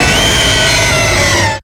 Cri de Kyogre dans Pokémon X et Y.